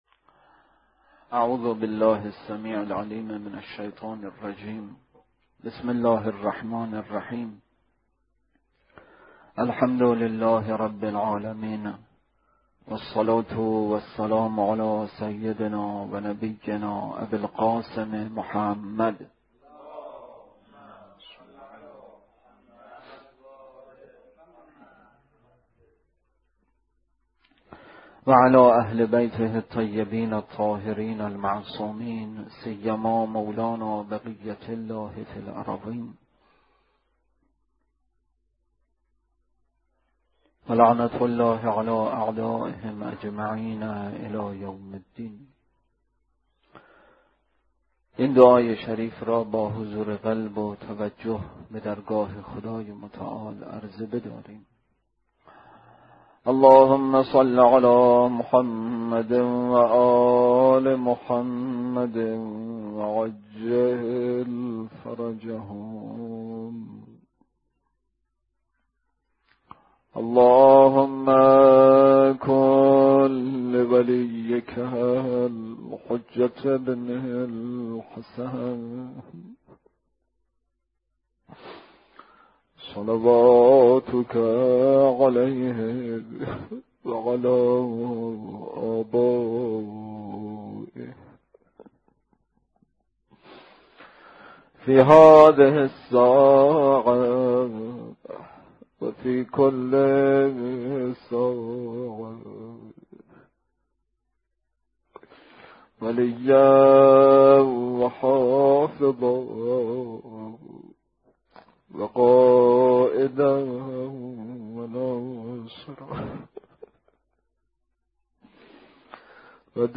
روضه: روایت ریان بن شبیب